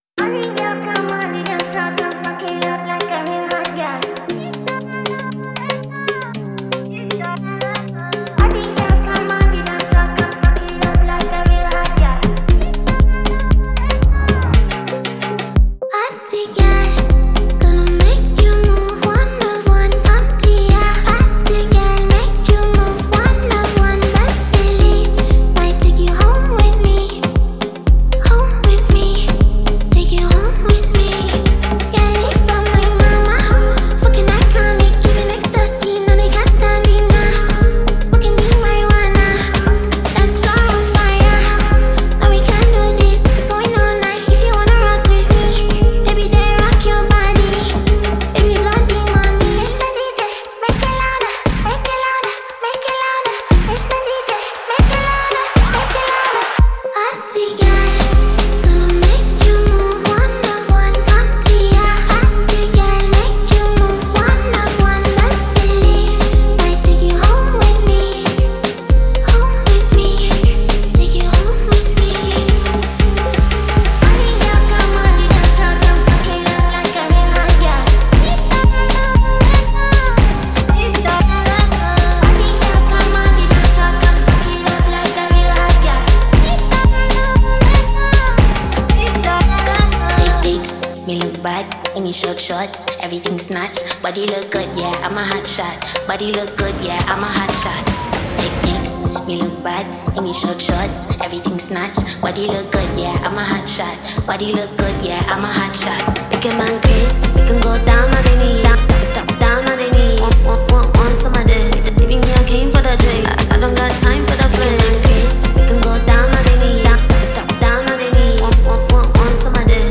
Ghanaian female artiste